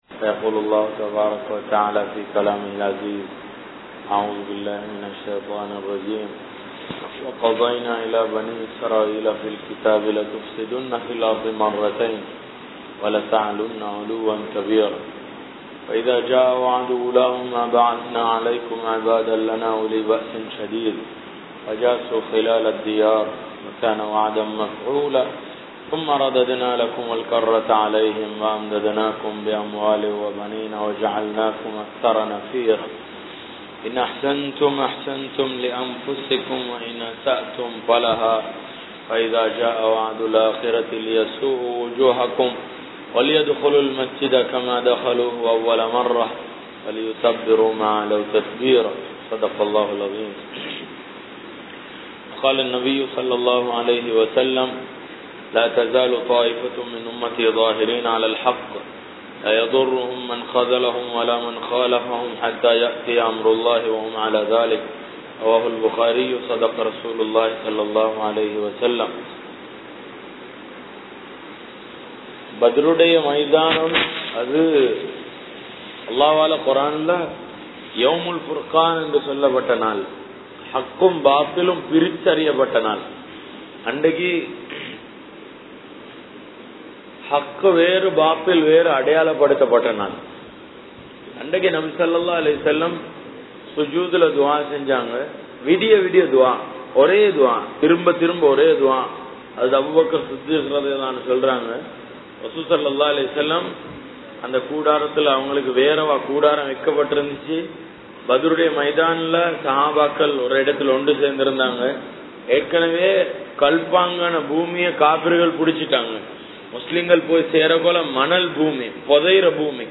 Shahabaakkalum Aqeethavum (ஸஹாபாக்களும் அகீதாவும்) | Audio Bayans | All Ceylon Muslim Youth Community | Addalaichenai
Kamachoda Jumua Masjith